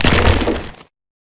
creaking_attack2.ogg